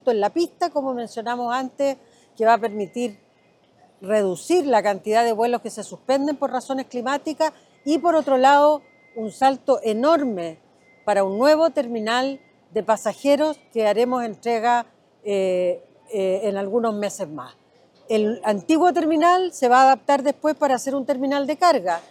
Jessica López Saffie / Ministra MOP